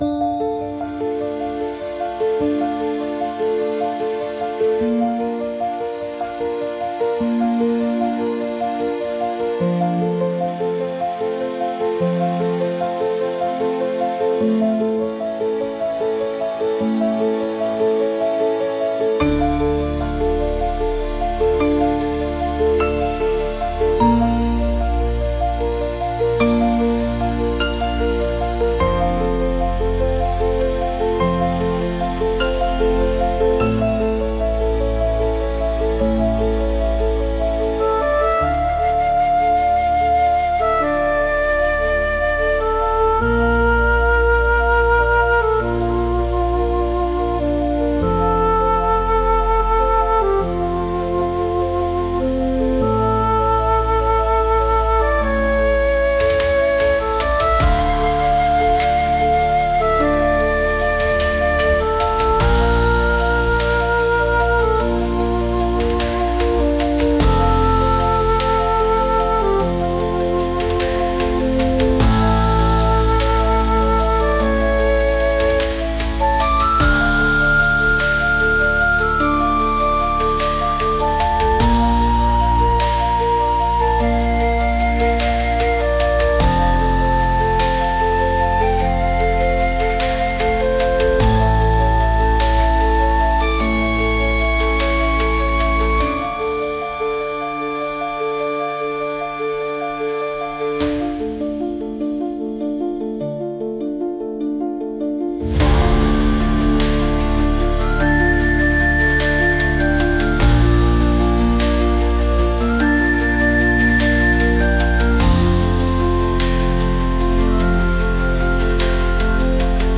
とても悲惨な結末を迎えたときのBGM